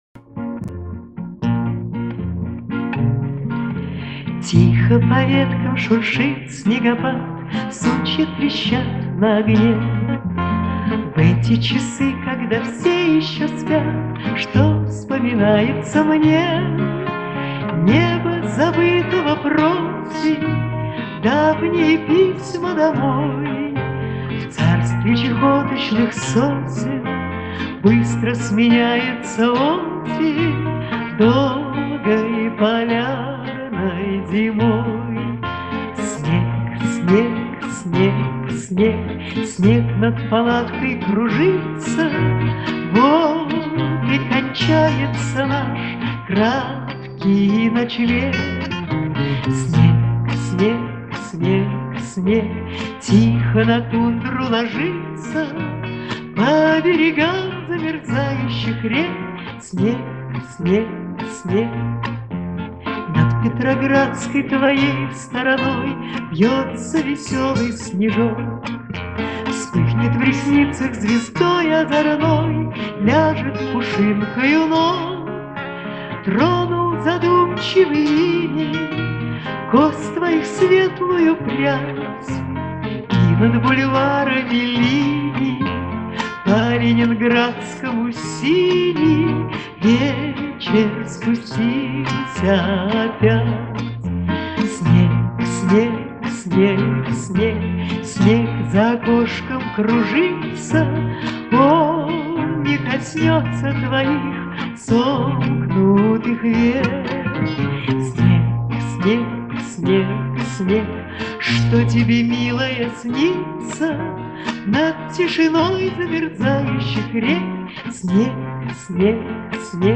Запись снята мною с видео и она концертная.